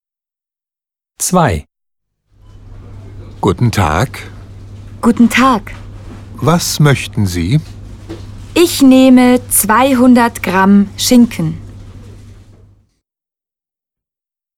Dialog 2: